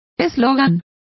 Complete with pronunciation of the translation of slogan.